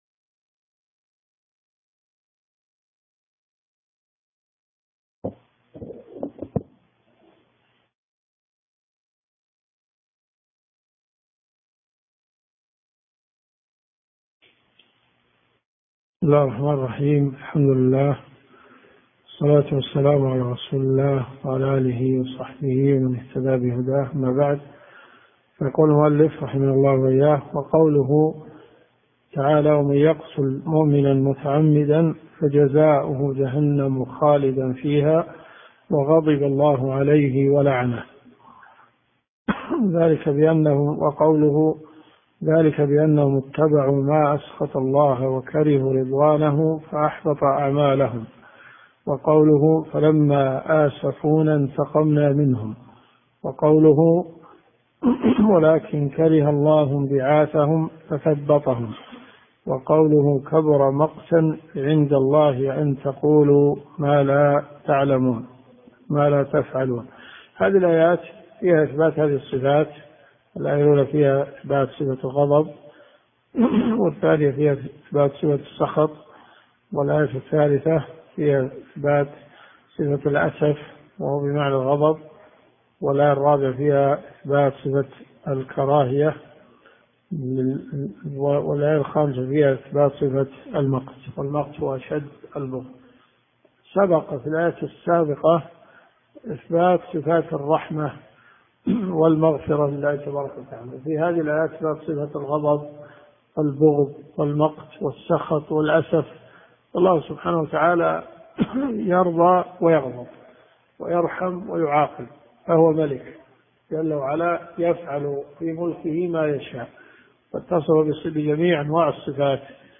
الدروس الشرعية